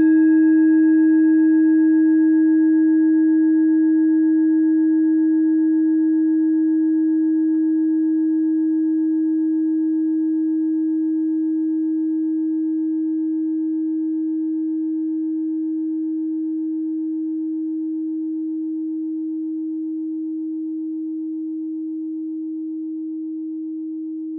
Tibet Klangschale Nr.1, Planetentonschale: Eros-Ton
Klangschalen-Gewicht: 670g
Der Klang einer Klangschale besteht aus mehreren Teiltönen.
Die Klangschale hat bei 307.12 Hz einen Teilton mit einer
Die Klangschale hat bei 316.4 Hz einen Teilton mit einer
klangschale-tibet-1.wav